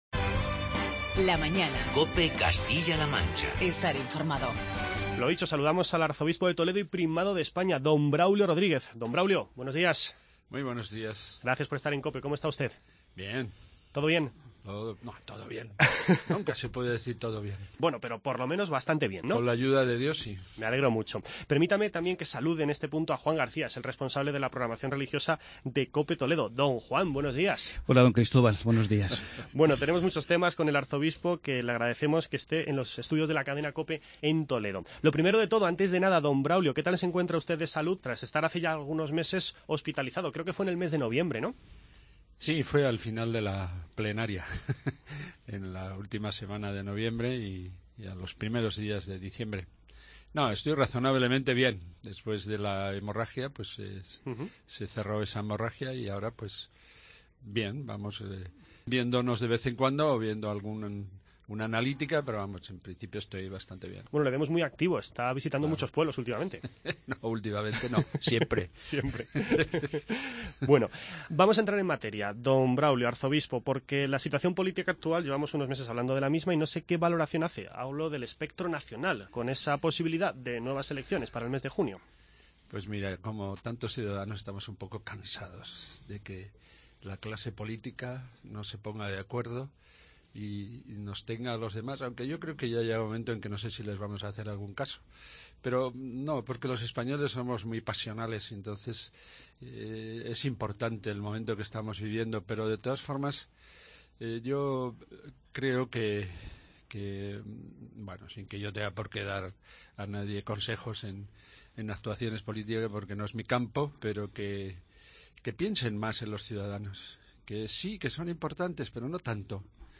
Escuche la entrevista con el arzobispo de Toledo y Primado de España, don Braulio Rodríguez, con el que abordamos la situación política actual, su reunión de este viernes con el líder de Podemos en Castilla-La Mancha, el futuro de la escuela concertada o las muchas actividades que la Archidiócesis de Toledo está celebrando durante este mes de marzo.